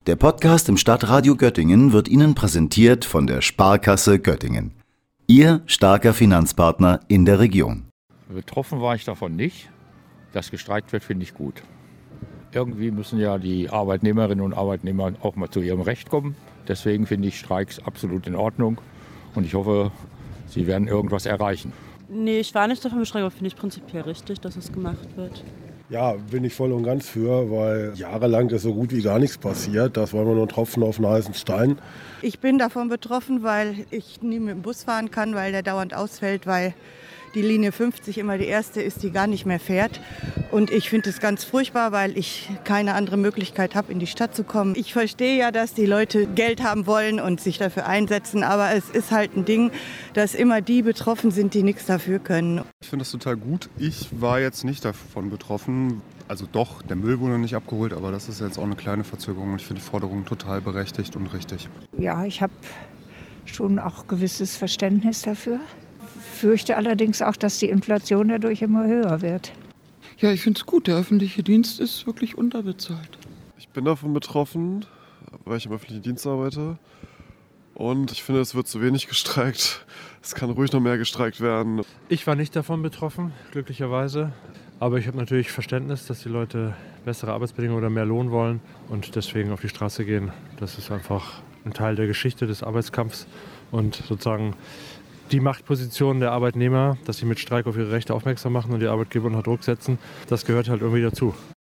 Wir wollten von den Göttingerinnen und Göttingern wissen, was sie von den Streiks halten und ob sie selber auch davon betroffen waren.